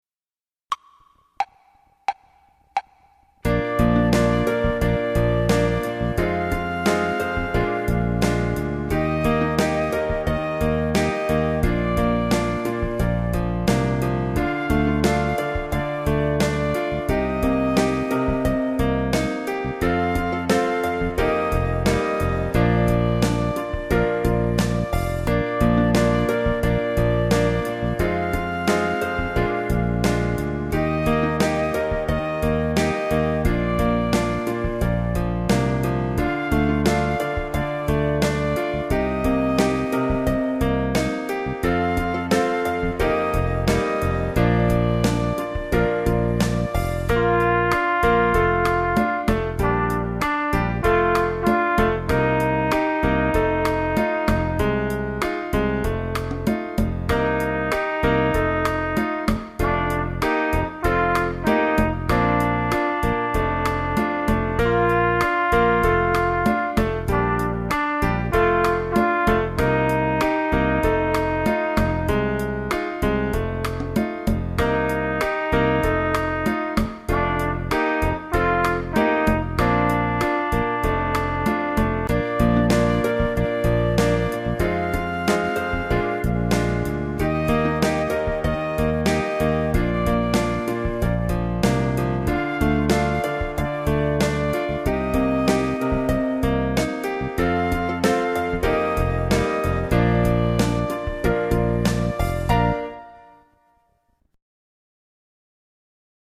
5 Samba 5 (melody).mp3